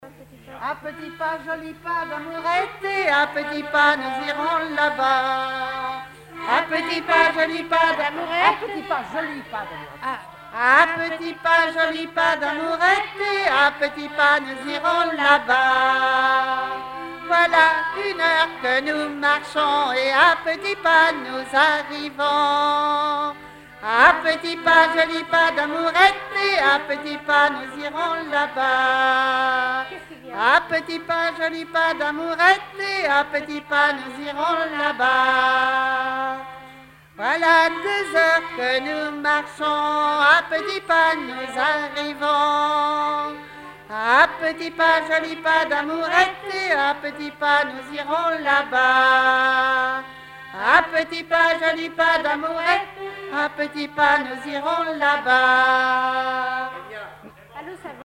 institutrices lors d'une animation musicale
gestuel : à marcher
Genre énumérative
répertoire d'airs à danser